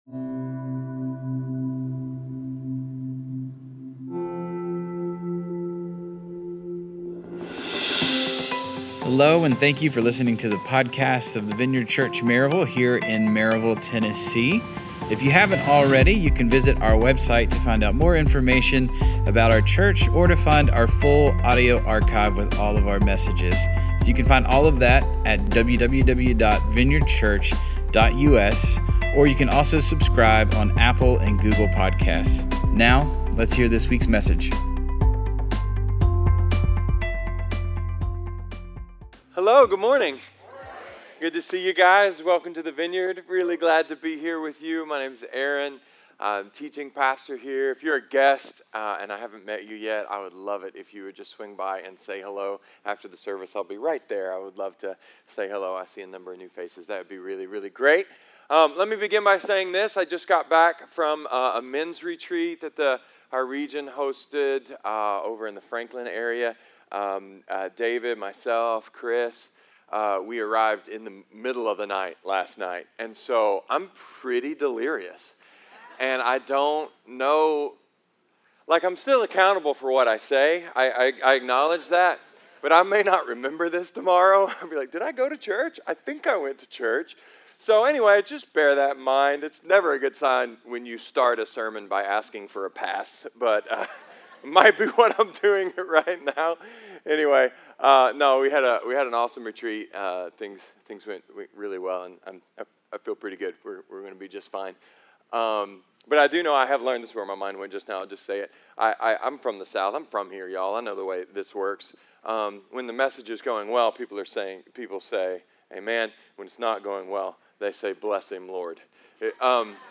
A sermon about inner rings, outer rings and gift of passage that only you can give.